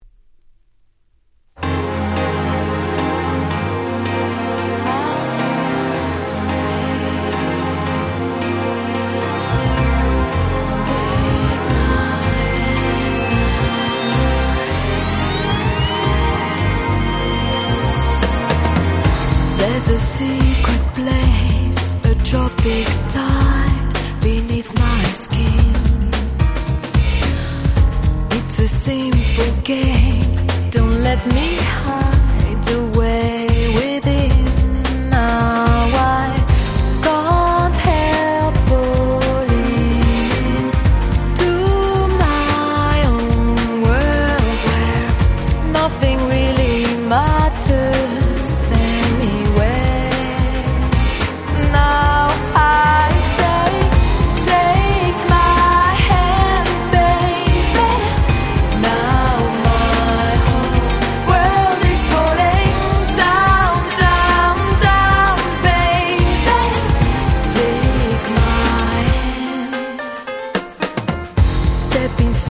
UK出身の美人女性シンガーでシングルは93年からリリースしておりました。
Crossoverな層に受けそうな非常にオシャレな楽曲ばかりです！
Bossa